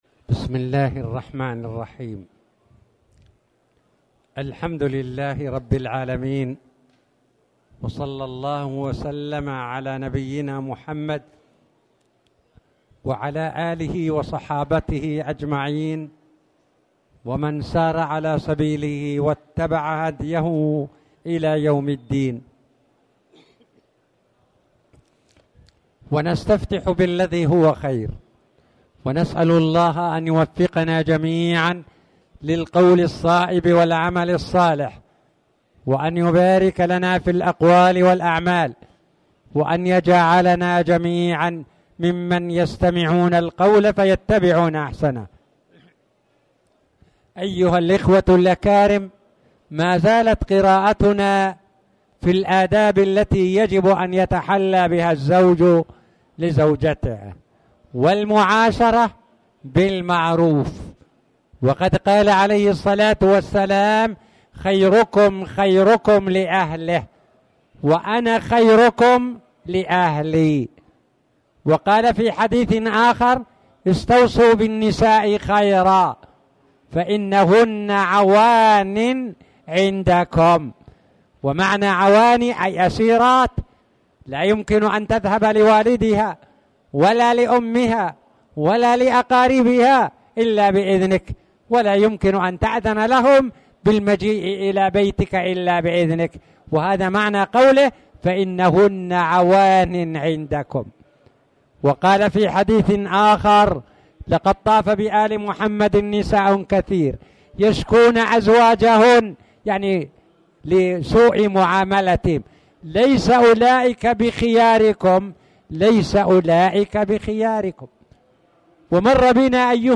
تاريخ النشر ٢٩ جمادى الأولى ١٤٣٨ هـ المكان: المسجد الحرام الشيخ